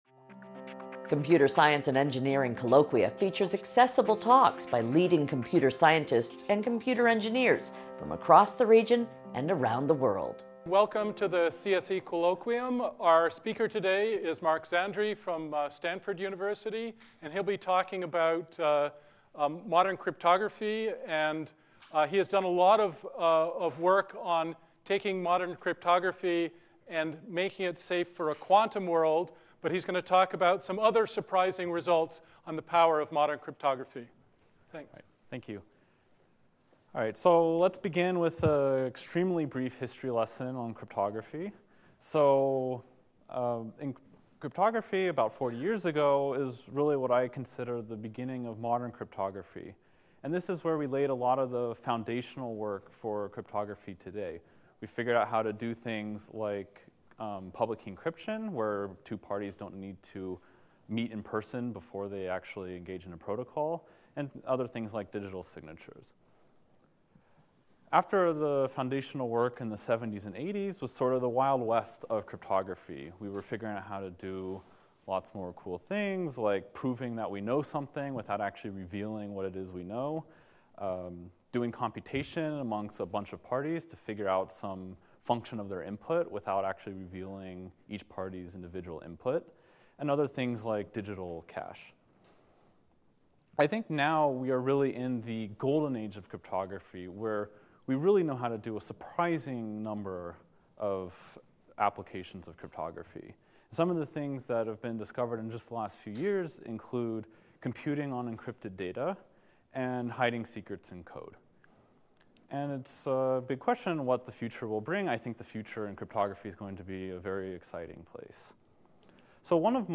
CSE 520 Colloquium